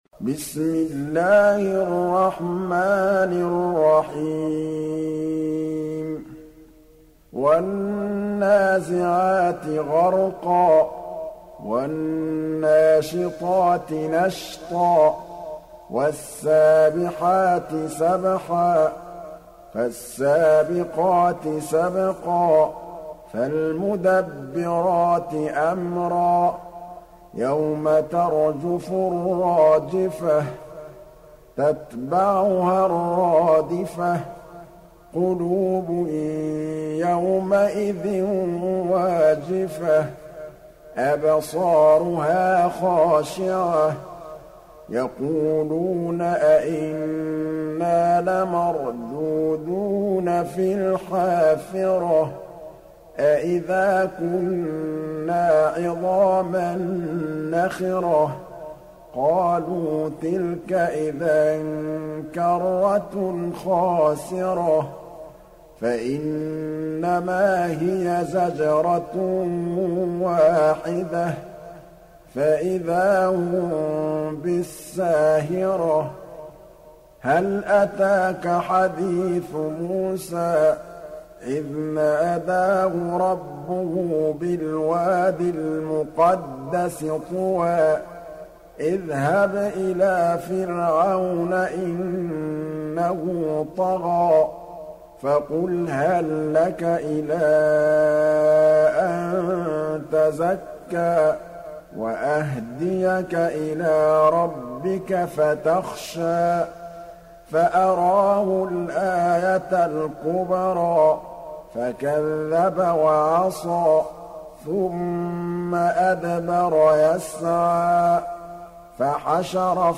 دانلود سوره النازعات mp3 محمد محمود الطبلاوي (روایت حفص)